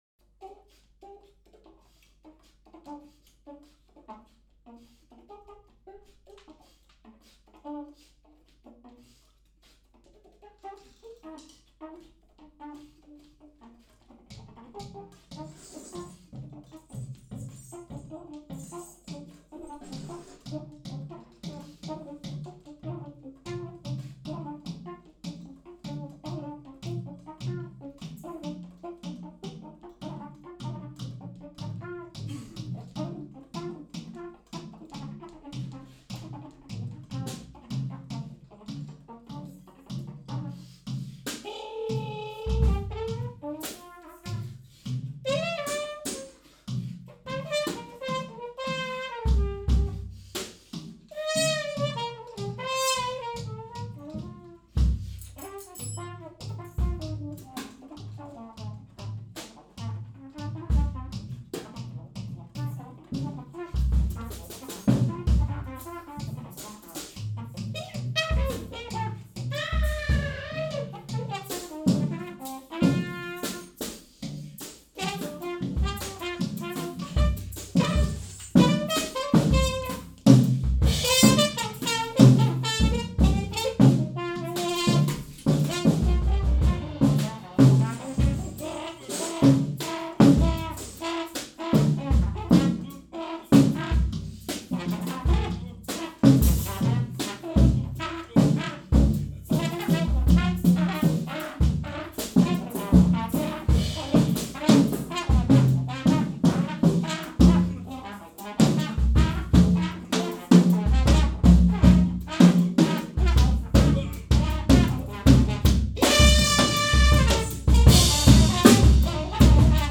Multitrack Recording